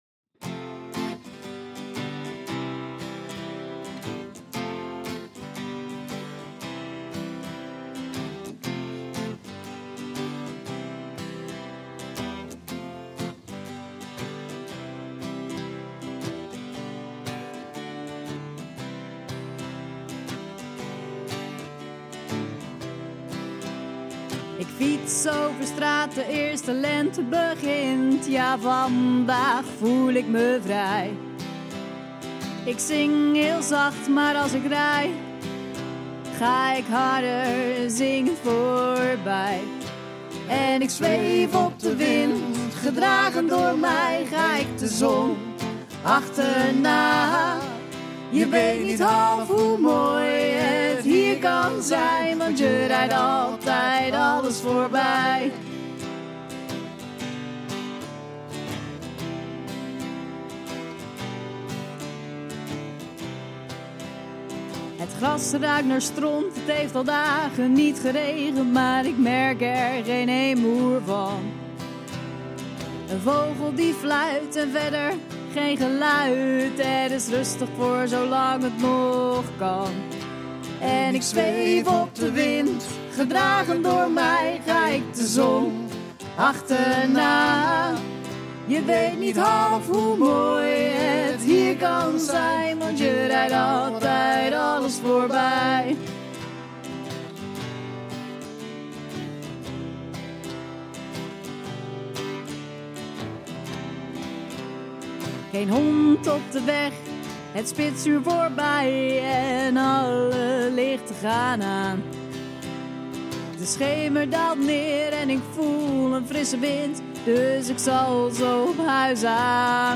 Puur, twee gitaren en twee zangstemmen.